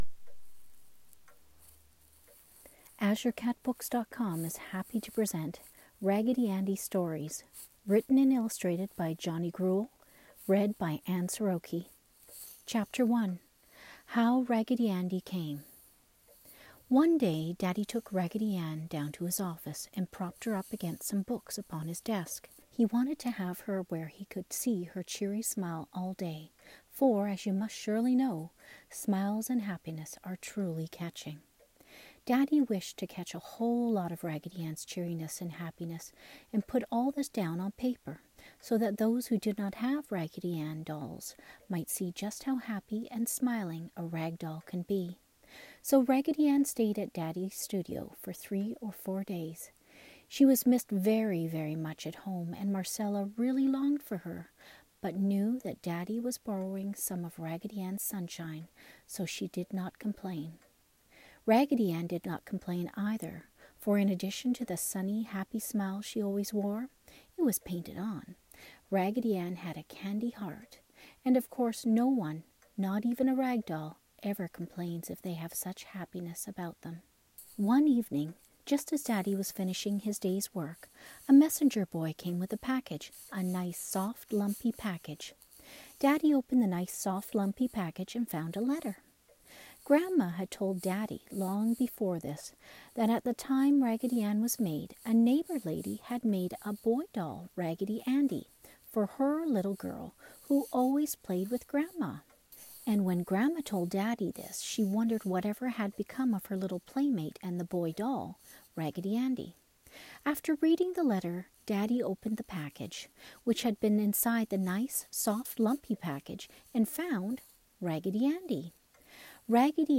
classic children's literature, children's stories, story time podcast, bed time story